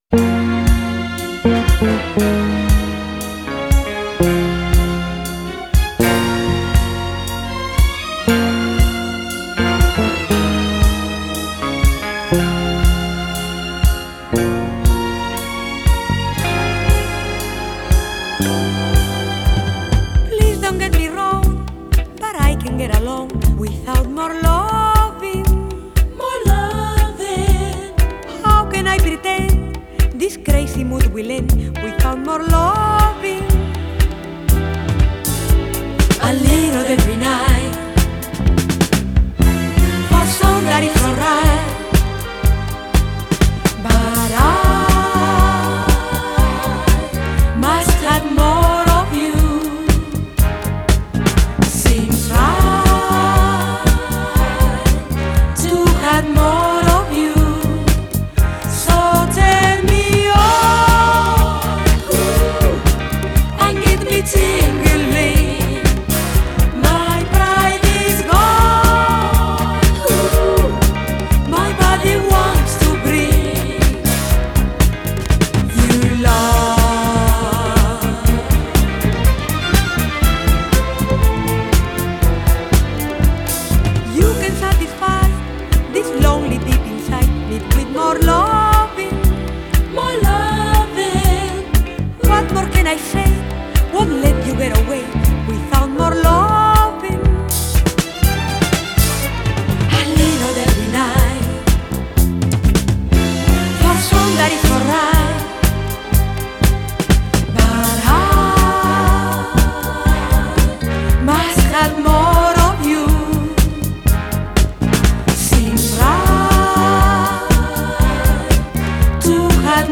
Genre: Funk / Soul, Disco